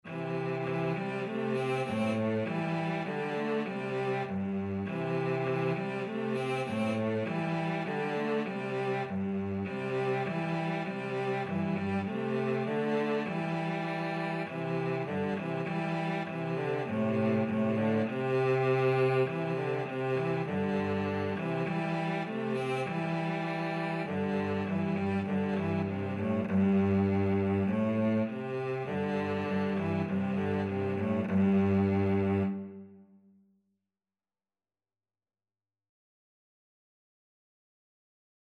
Cello 1Cello 2
2/2 (View more 2/2 Music)